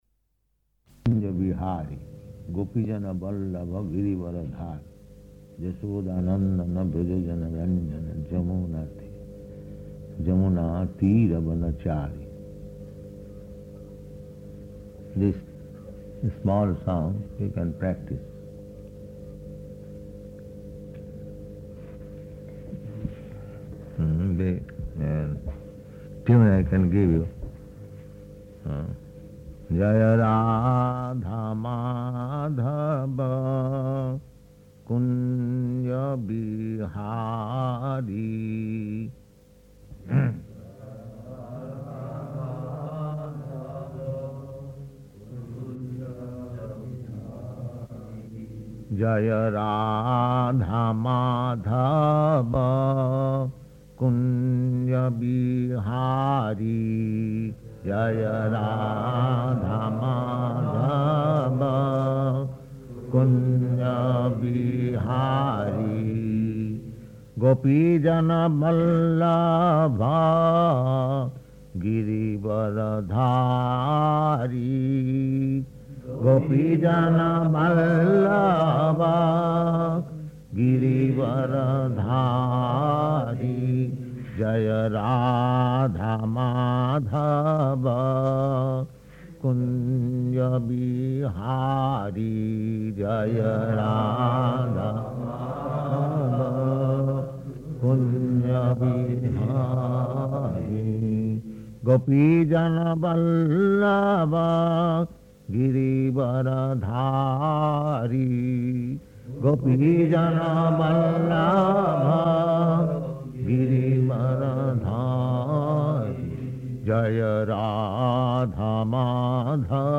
Type: Purport
Location: Gorakphur
[Prabhupāda sings each line and devotees respond] [break] [then kīrtana with mṛdaṅga and karatālas ] [12:20] [break] This is actual picture of Kṛṣṇa, rādhā-mādhava giri-vara-dhārī.